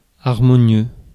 Ääntäminen
Synonyymit harmonique velvétien Ääntäminen France: IPA: [aʁ.mɔ.njø] Haettu sana löytyi näillä lähdekielillä: ranska Käännöksiä ei löytynyt valitulle kohdekielelle.